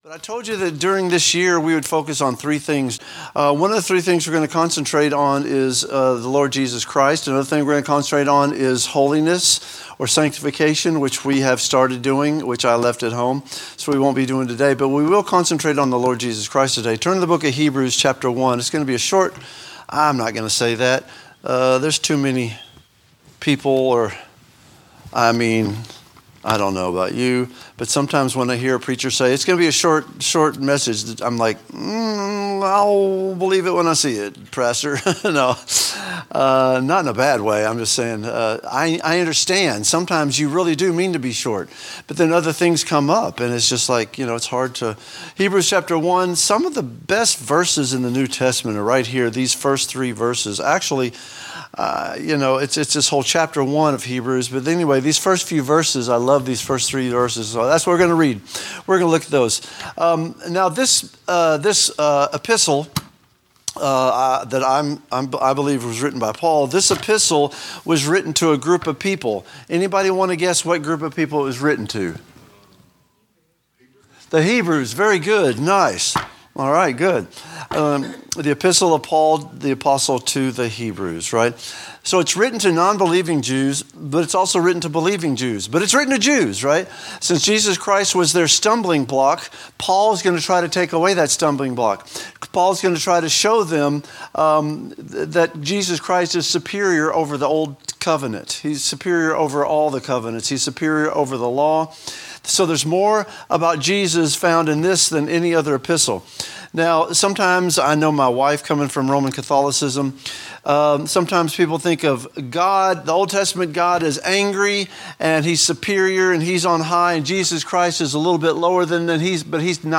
A message from the series "General Teaching."